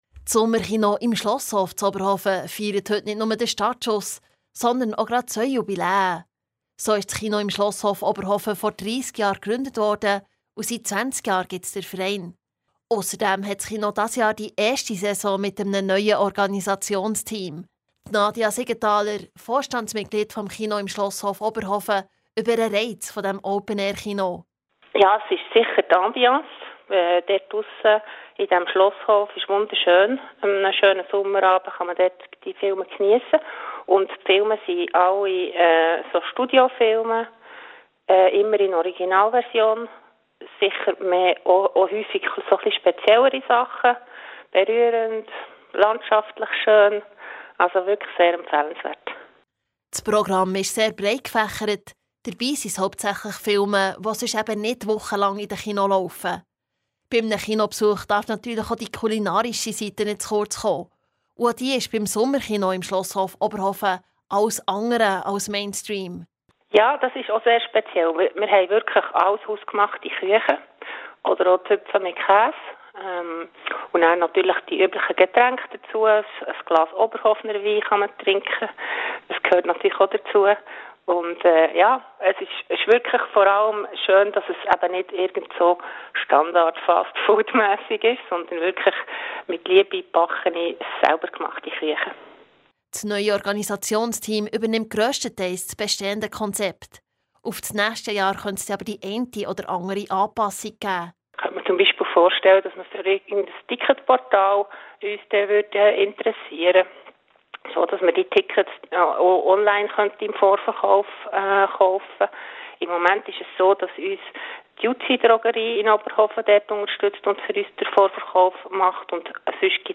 Kurzbericht im Radio BEO